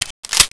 Index of /csko16/sound/weapons/usp_avatar
de_clipin.wav